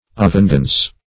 Avengeance \A*venge"ance\, n.